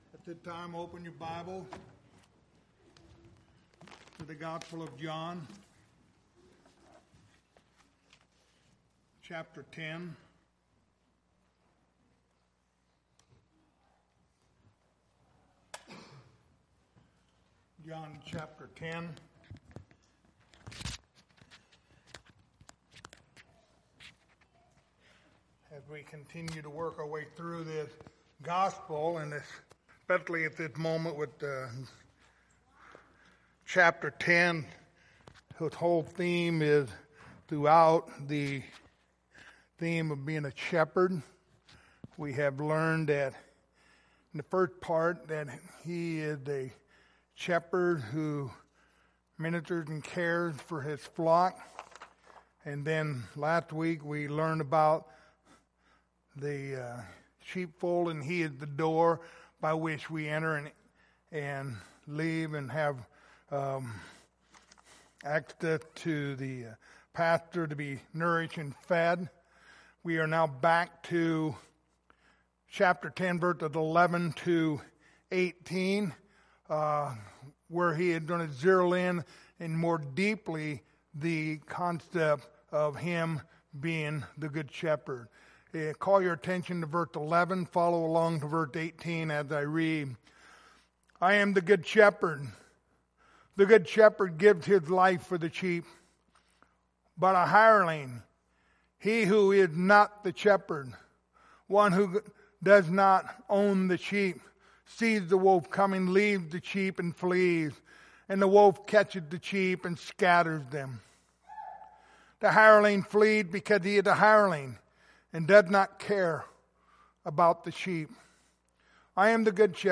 Passage: John10:11-18 Service Type: Wednesday Evening Topics